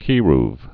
(kērv)